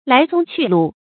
來蹤去路 注音： ㄌㄞˊ ㄗㄨㄙ ㄑㄩˋ ㄌㄨˋ 讀音讀法： 意思解釋： 見「來蹤去跡」。